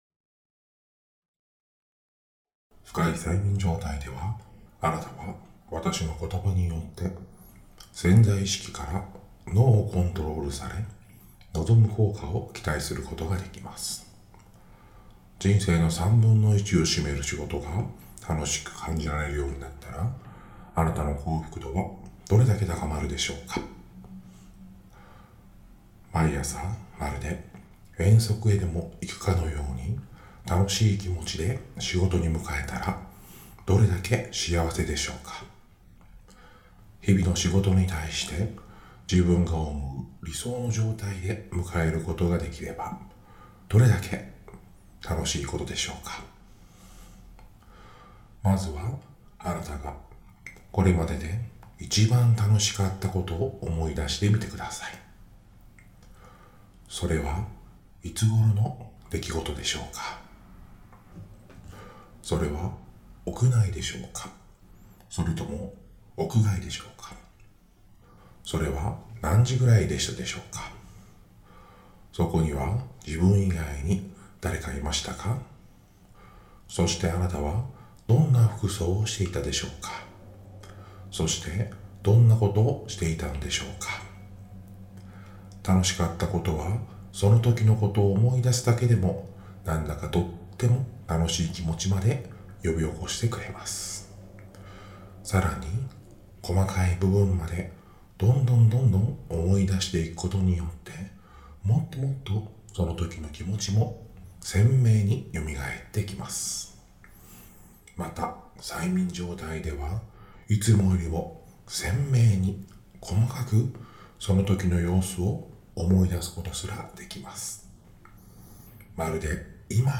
追加催眠音声 仕事が楽しくなる